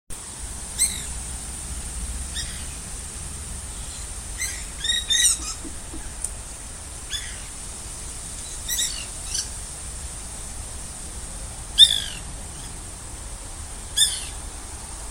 White Woodpecker (Melanerpes candidus)
Location or protected area: Villa Rosa
Condition: Wild
Certainty: Photographed, Recorded vocal
carpintero-blanco.mp3